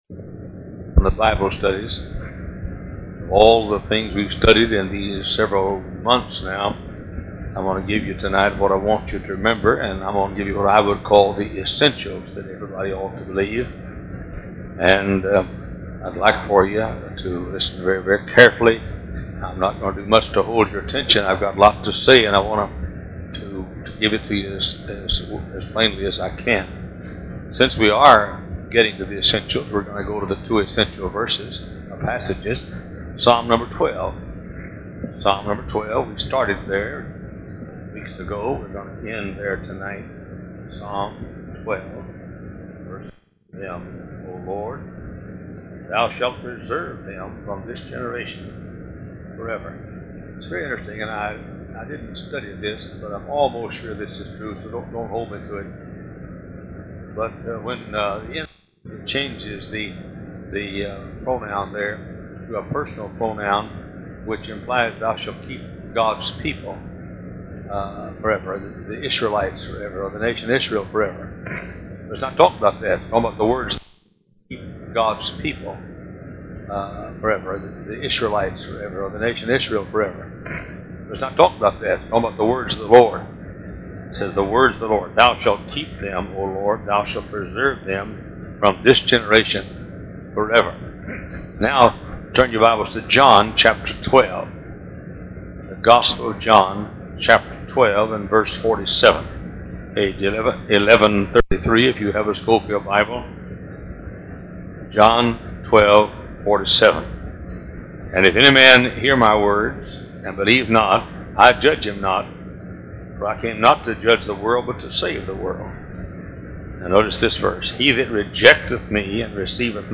King James Bible Study